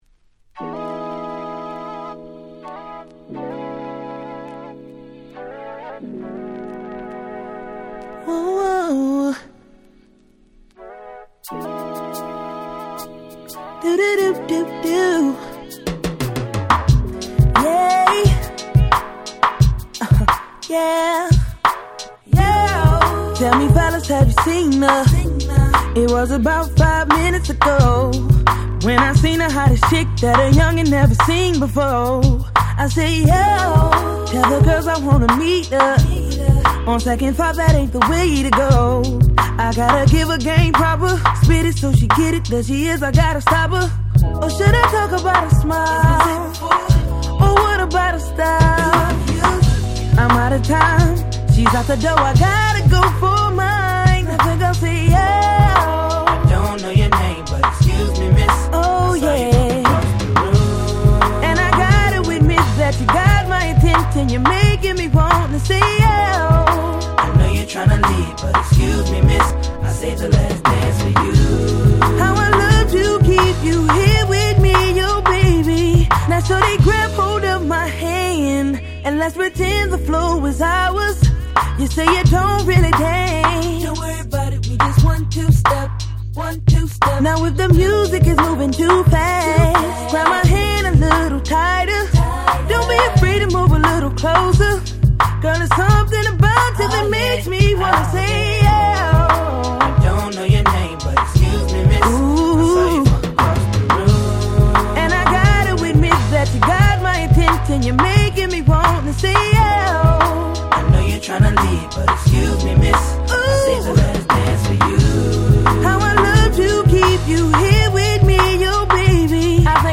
05' Super Hit R&B !!